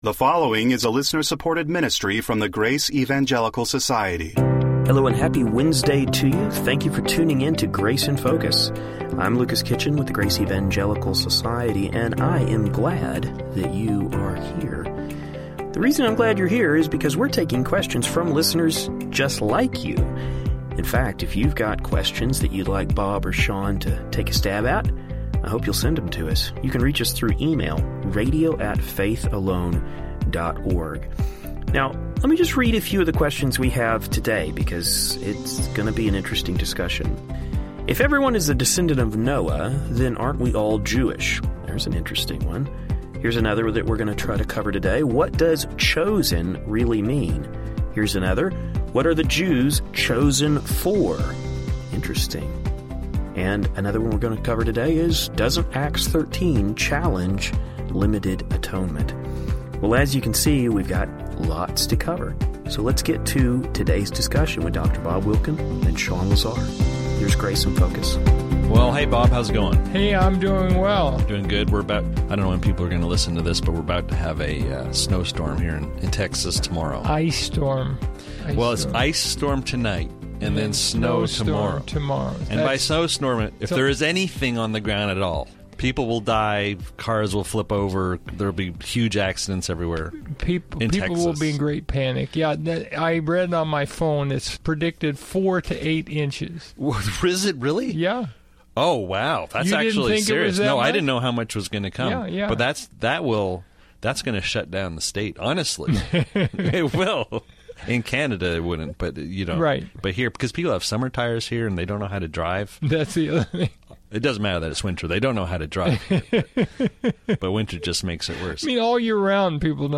Today, they will be responding to a question regarding the descendants of Noah. In addition, we will hear the guys address the word “chosen” and its biblical meaning. What does the bible mean when it says the Jews were “chosen?”